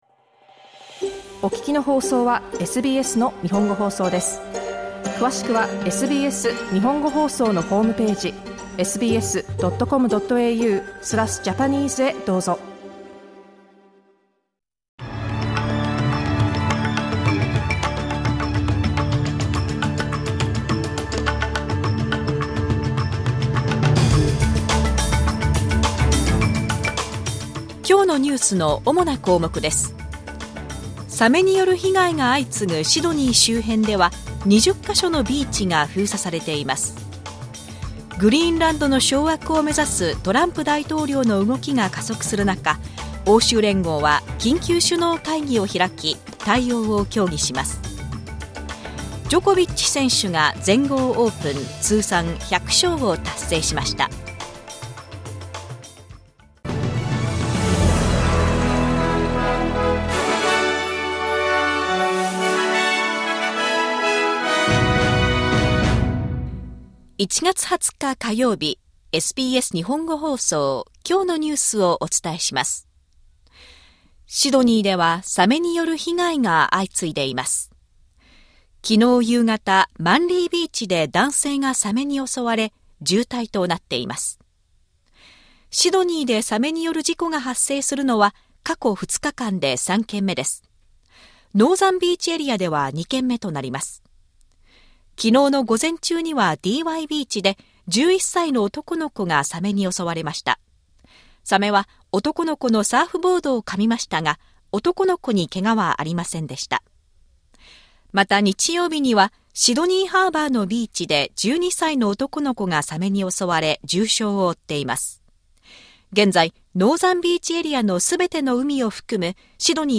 SBS日本語放送ニュース1月20日火曜日